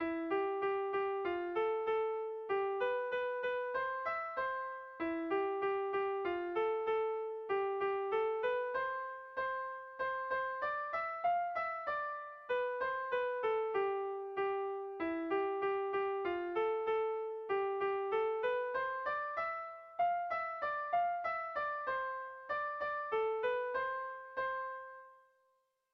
Bertsotan jo ditugu - Bertso melodies - BDB.
Kontakizunezkoa
A1A2BA2D